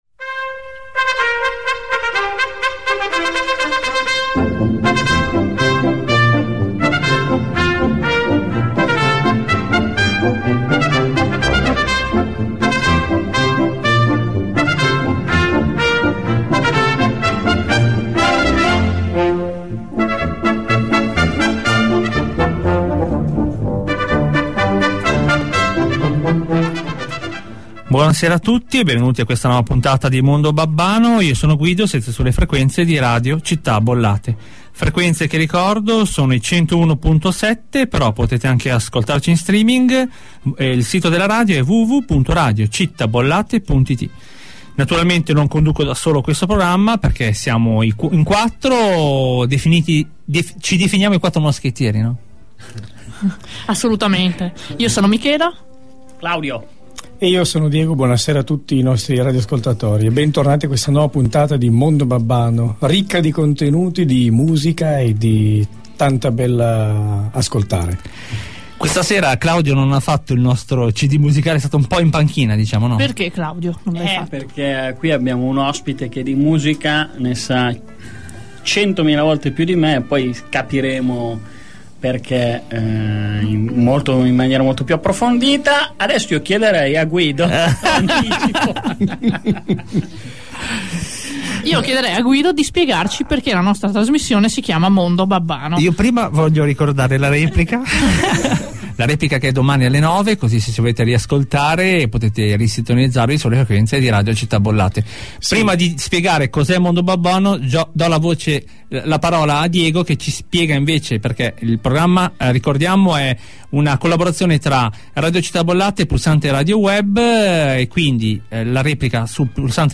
Intervista a “Radio Città Bollate”
Intervista realizzata l’11 febbraio 2010 a “Mondo Babbano”, programma condotto da un gruppo di persone non vedenti su Radio Città Bollate.